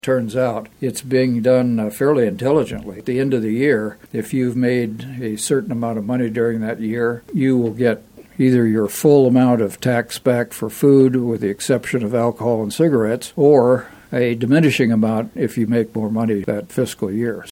Dodson was a guest on KMAN’s In Focus Monday.